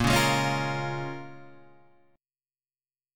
A# Minor 7th